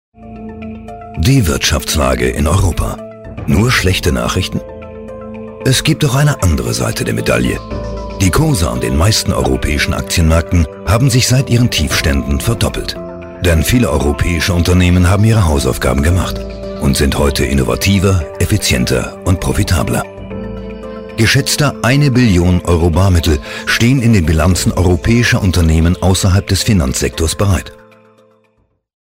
Sachtext DE